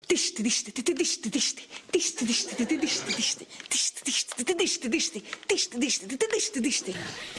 Звук бодрой няни в работе